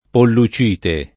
pollucite